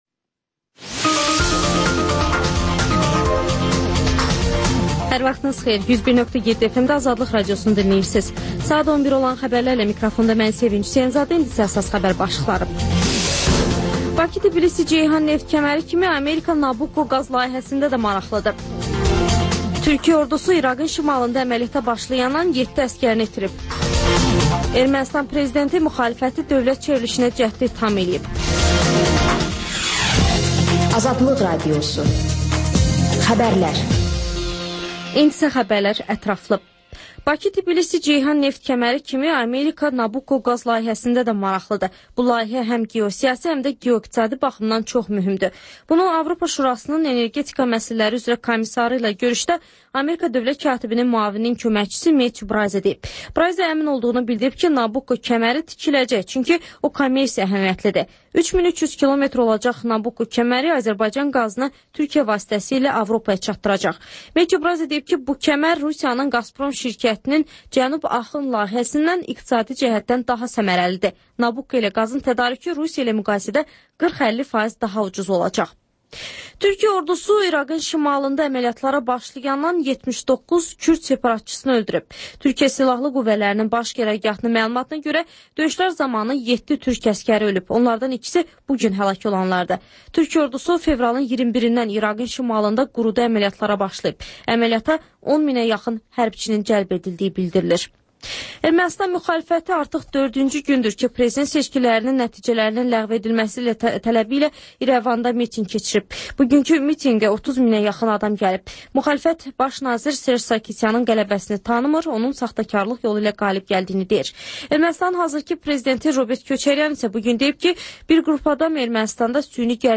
Xəbərlər, ardınca PANORAMA verilişi: Həftənin aktual mövzusunun müzakirəsi.